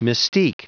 Prononciation du mot mystique en anglais (fichier audio)
Prononciation du mot : mystique